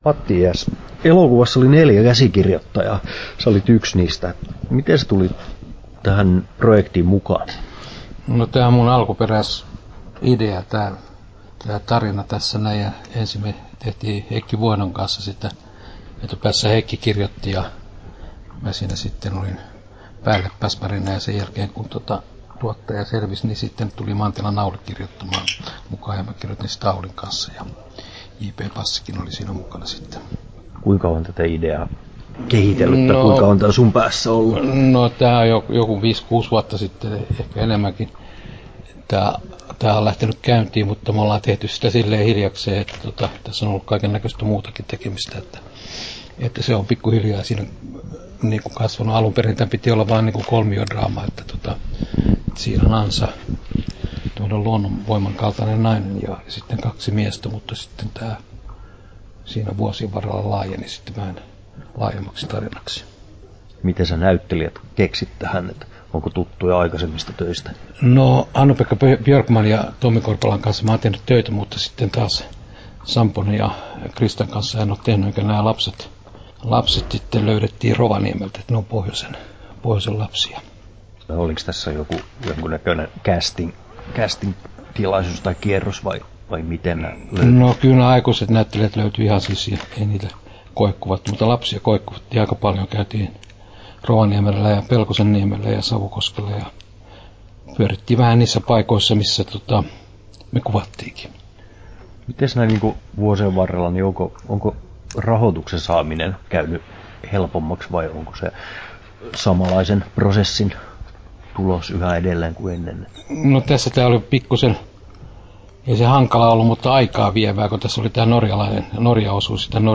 Matti Ijäksen haastattelu Kesto